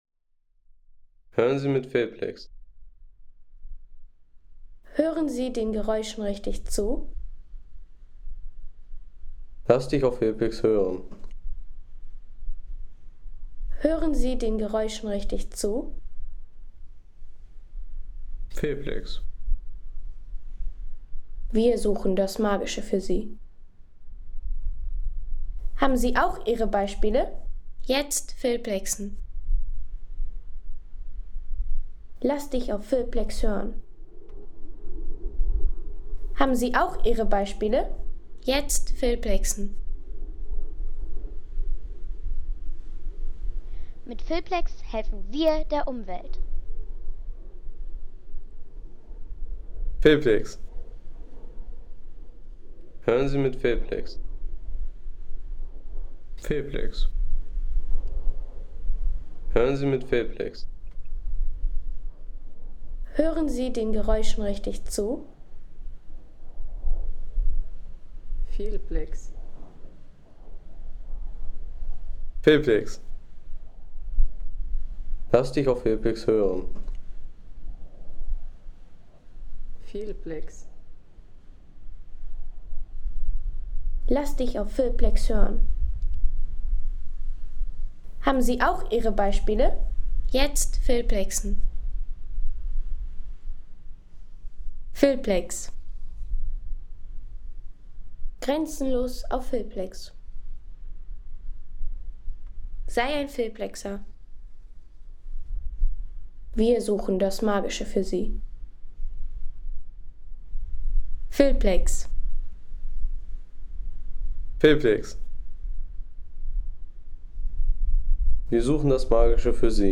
Der Klang eisiger Höhen Home Sounds Landschaft Berge Der Klang eisiger Höhen Seien Sie der Erste, der dieses Produkt bewertet Artikelnummer: 265 Kategorien: Landschaft - Berge Der Klang eisiger Höhen Lade Sound.... Sturmspitze in den Zillertaler Alpen – Der Klang eisiger Höhen.